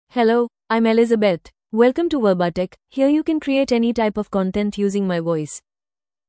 FemaleEnglish (India)
Elizabeth is a female AI voice for English (India).
Voice sample
Female
Elizabeth delivers clear pronunciation with authentic India English intonation, making your content sound professionally produced.